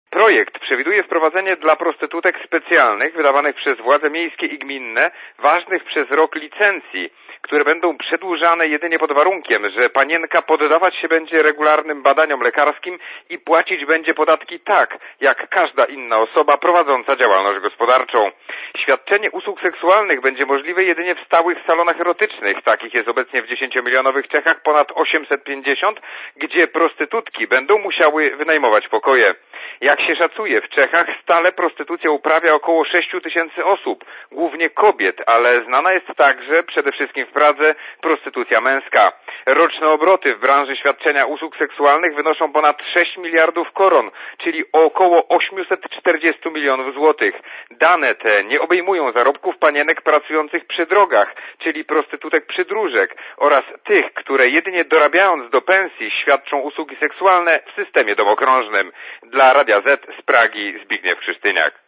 Posłuchaj relacji czeskiego korespondenta Radia Zet